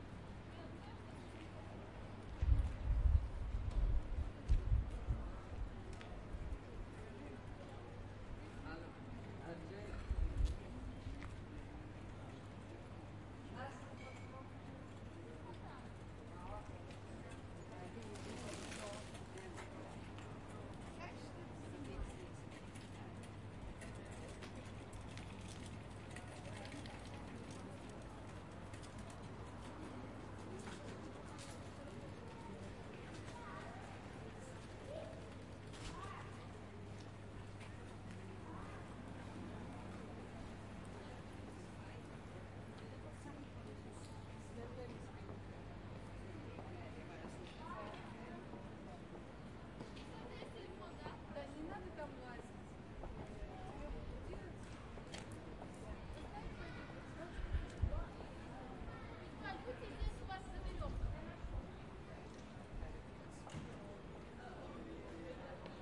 描述：一个乞丐和他的儿子在地铁里。铃声响起之前，在门外，男子开始了他的演讲，很快就被嘈杂的地铁所掩盖。阿根廷，布宜诺斯艾利斯。2004年5月。用几个领夹式舒尔MC50全向话筒、PSP2前置放大器夏普MD录音机
标签： 地铁 语音 现场录音
声道立体声